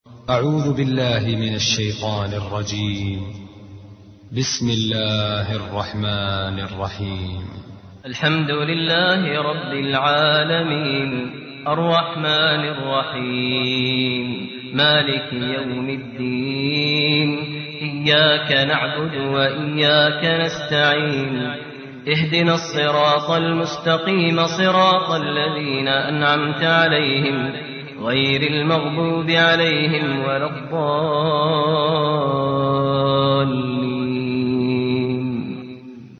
قرآن كريم للشيخ ماهر المعيقلي
القران بصوت ماهر المعيقلي